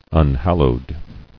[un·hal·lowed]